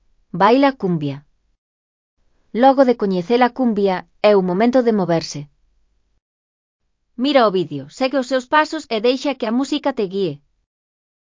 AUD_MUD_6PRI_REA02_BAILA_CUMBIA_V01.mp3